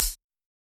Index of /musicradar/ultimate-hihat-samples/Hits/ElectroHat A
UHH_ElectroHatA_Hit-13.wav